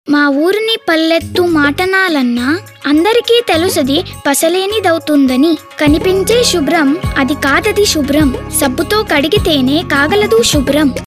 Handwashing with soap before eating - Clean India Radio PSA (Telugu)
Radio spot Hindi TSC children handwashing soap food eating